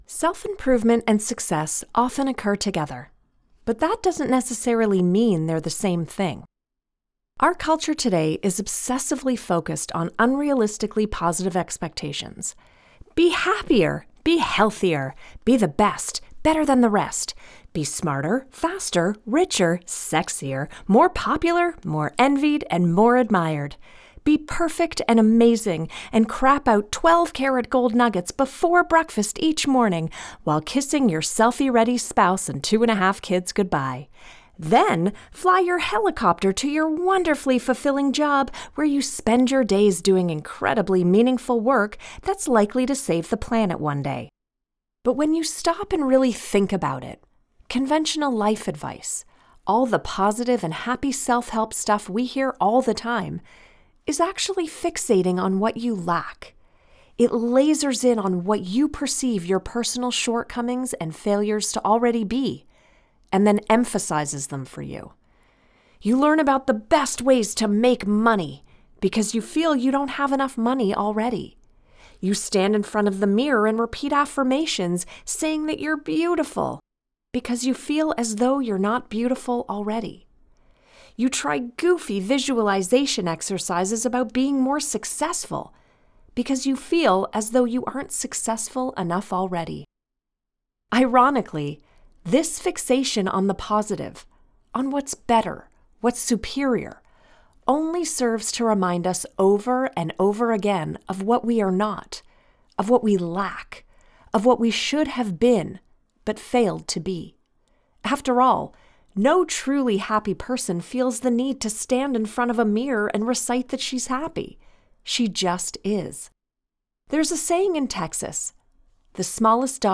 Self-Help Audiobook (Author Mark Manson)
🎙 Broadcast-quality audio
(RODE NT1 Signature Mic + Fully Treated Studio)
Middle Aged Female